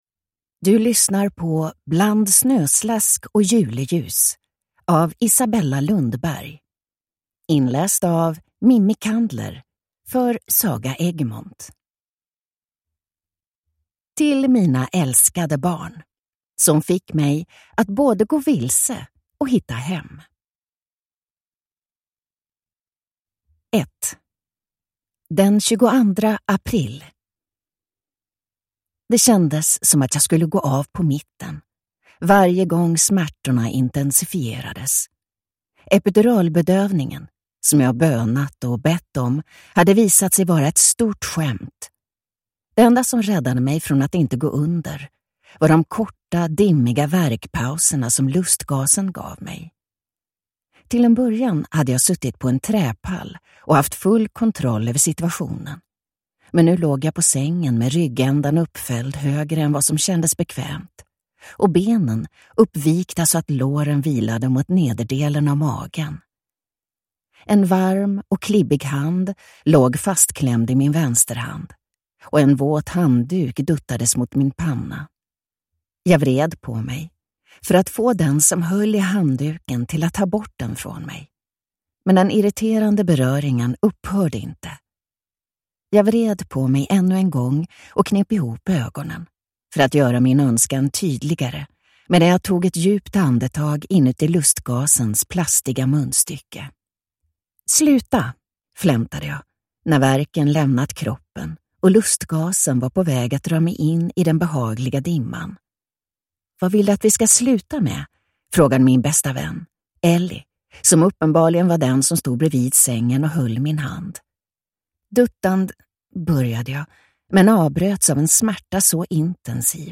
Bland snöslask och juleljus (ljudbok) av Isabella Lundberg